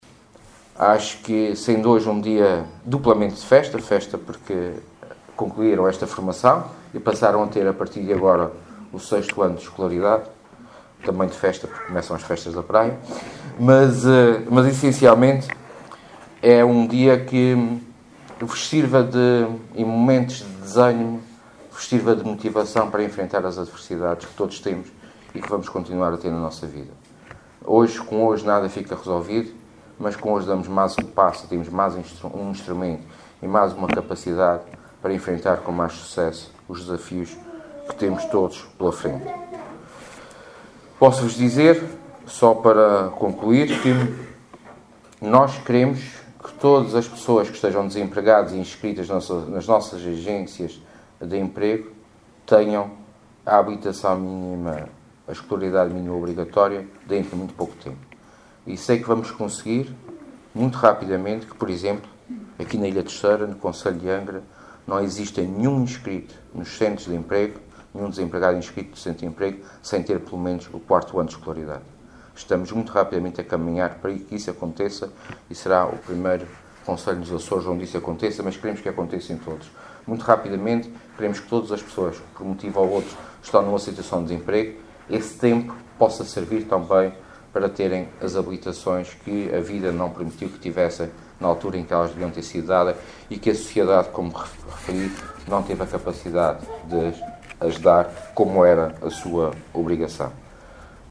A afirmação é do Vice-Presidente do Governo Regional, Sérgio Ávila, que falava na cerimónia de entrega de diplomas do 2.º ciclo aos formandos que concluíram os cursos de Aquisição Básica de Conhecimentos.